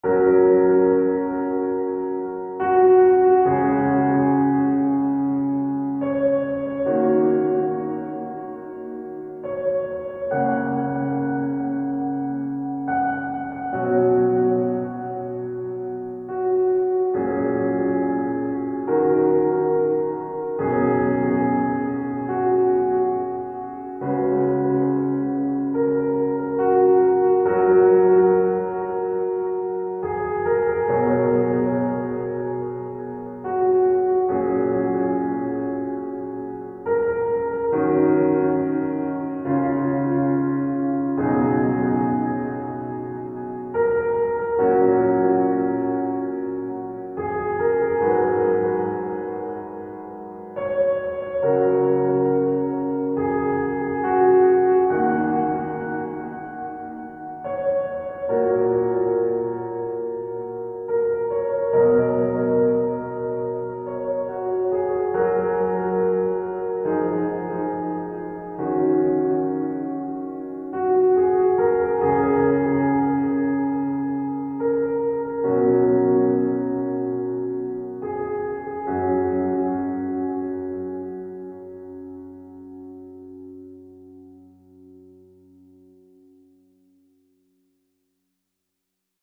未分類 ピアノ 懐かしい 穏やか 青春 音楽日記 よかったらシェアしてね！